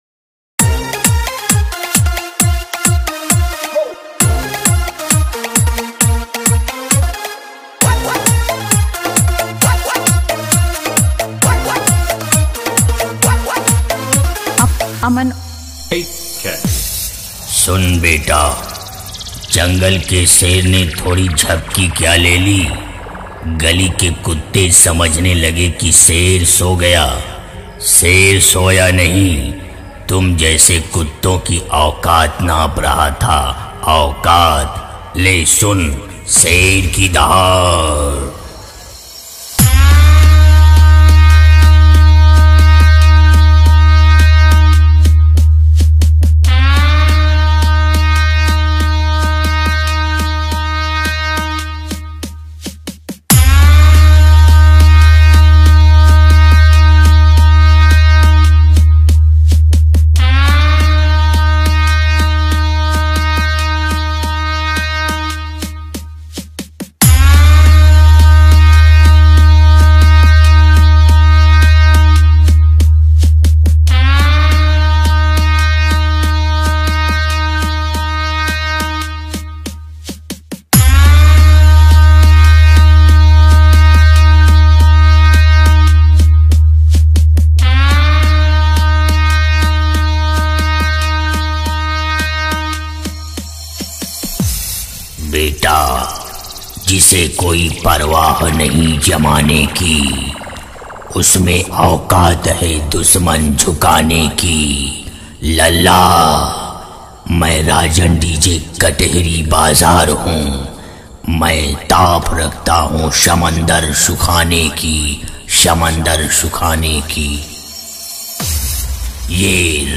DJ Remix